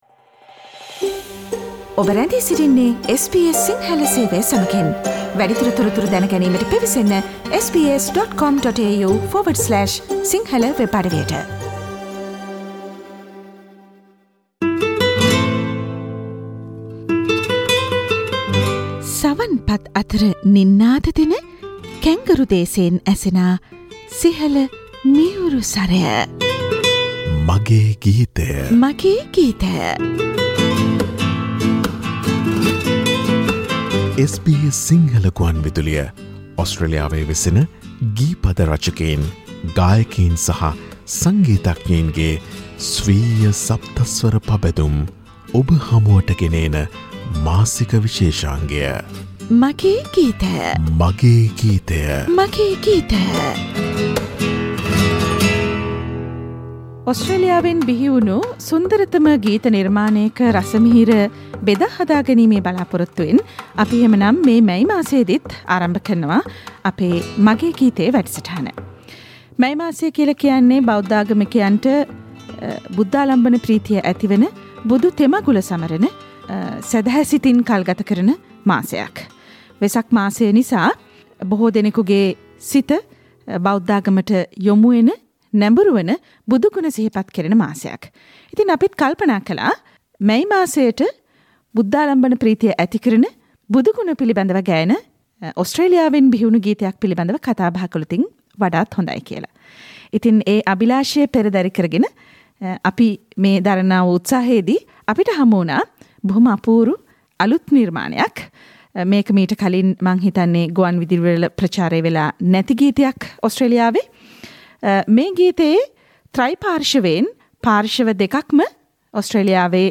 SBS සිංහල ගුවන්විදුලියේ 'මගේ ගීතය' මාසික සංගීතමය වැඩසටහනේ මෙවර කතාබහ මේ වෙසක් මාසයට ගැලපෙන බොදු බැති ගීයක් පිළිබඳවයි.